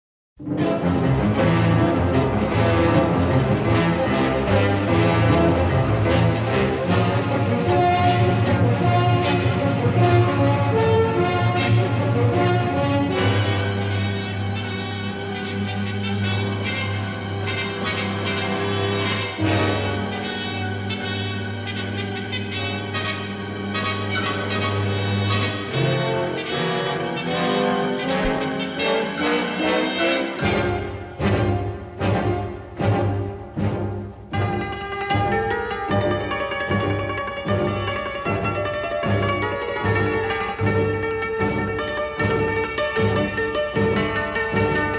in their definitive stereo editions
romantic, full-blooded approach